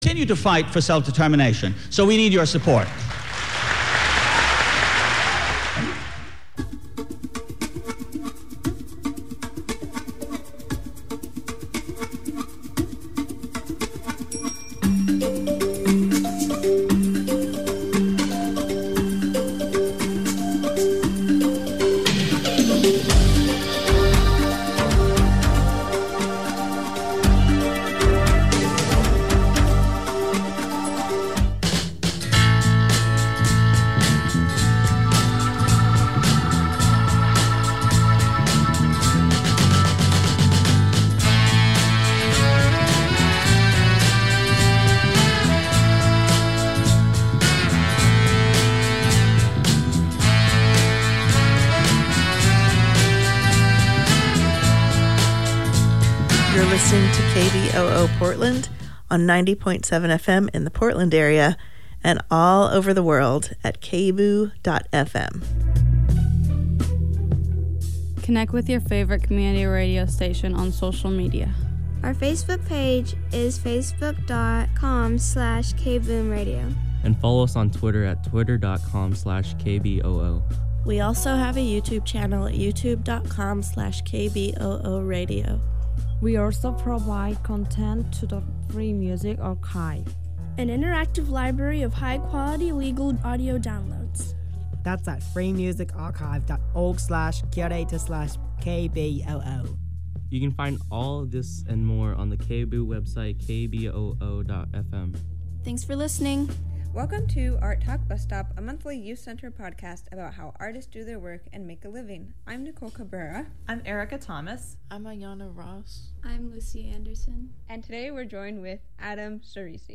Each month we interview an artist, maker, or creativ...